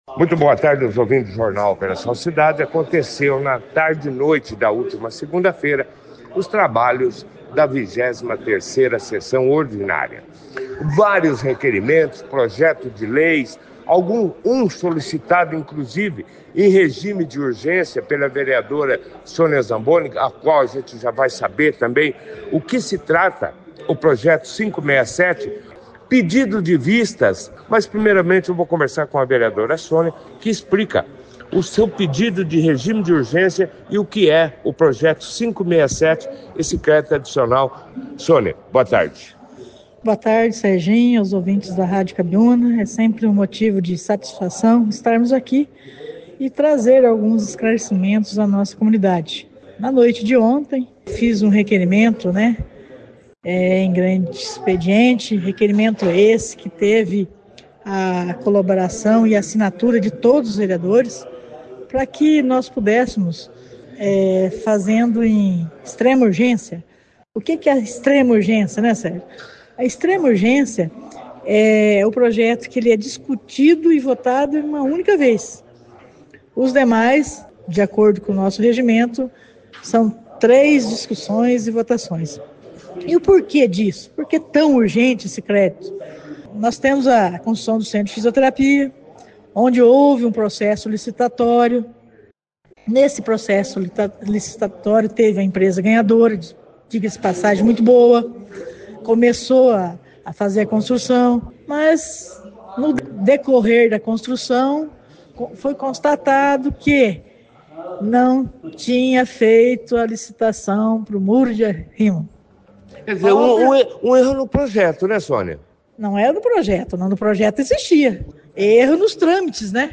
A 23ª Sessão Ordinária da Câmara Municipal de Bandeirantes foi realizada na última segunda-feira, 18 de agosto, no plenário do Legislativo. A reunião foi destaque na 2ª edição do Jornal Operação Cidade, desta terça-feira, 19 de agosto.
A vereadora Sônia Zamboni falou sobre seu pedido de regime de urgência para a aprovação do Projeto 567, que autoriza a abertura de crédito adicional para a continuidade das obras do Centro de Fisioterapia do município.